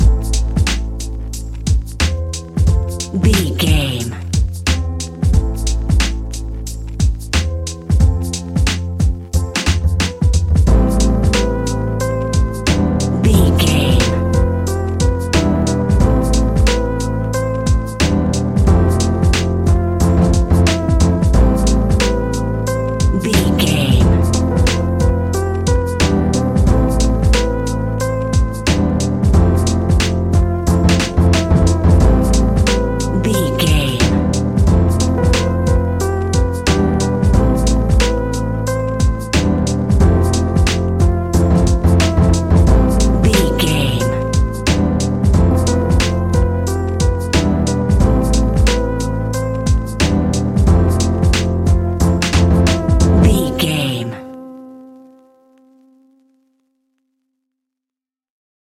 Ionian/Major
chilled
laid back
Lounge
sparse
new age
chilled electronica
ambient
atmospheric
instrumentals